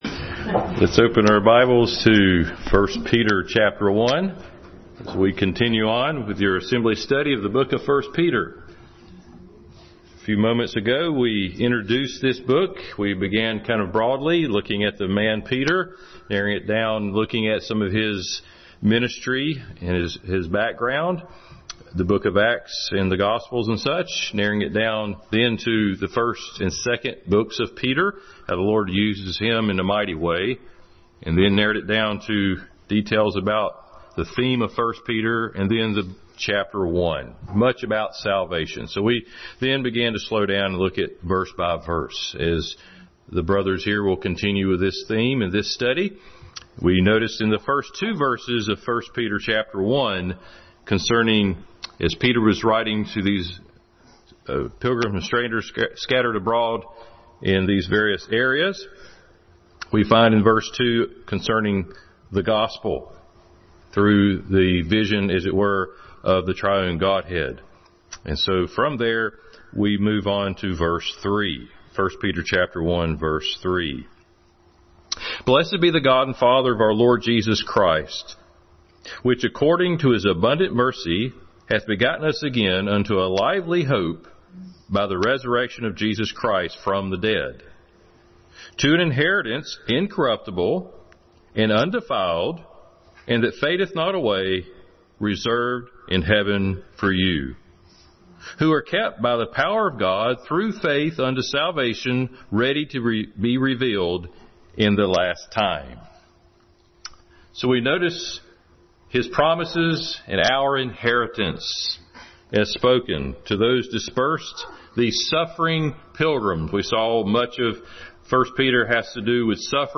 1 Peter 1:3-5 Passage: 1 Peter 1:3-5, Titus 3:5-6, John 3:3, 16, 1 Corinthians 15, Hebrews 7:11-13, 1Peter 1:23-24, 5:4 Service Type: Family Bible Hour